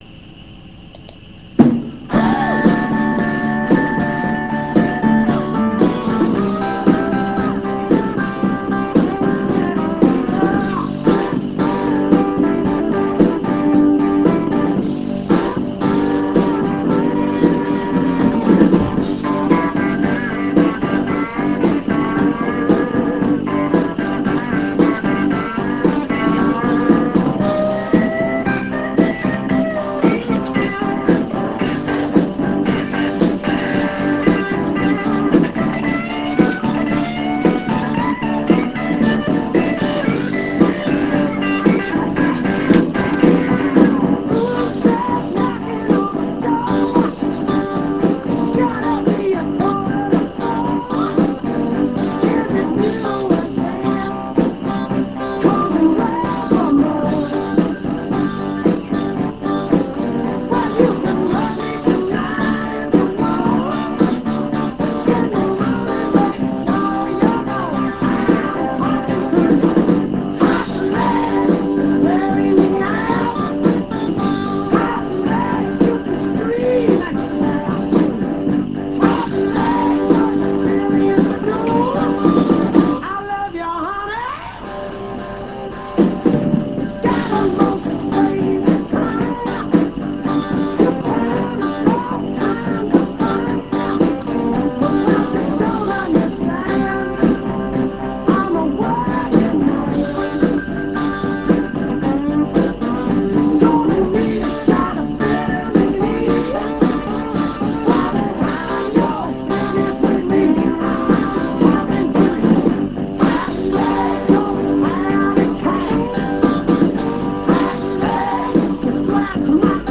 VOCAL!!